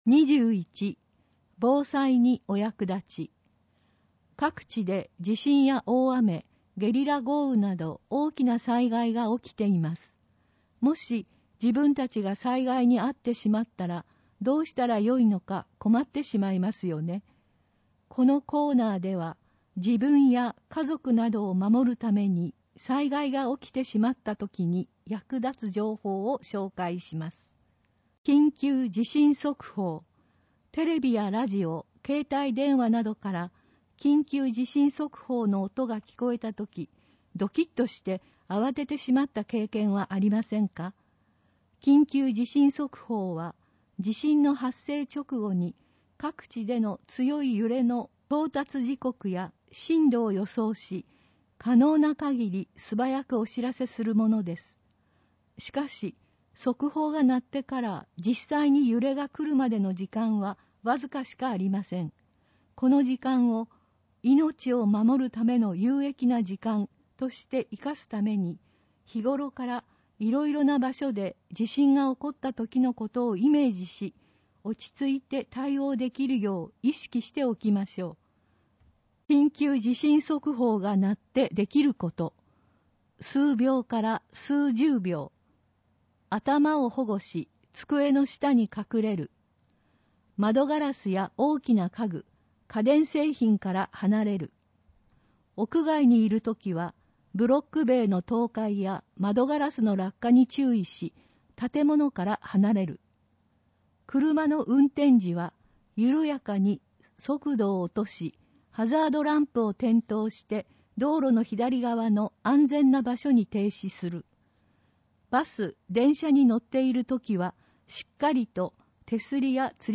広報とうごう音訳版（2021年1月号）